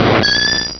Cri de Yanma dans Pokémon Rubis et Saphir.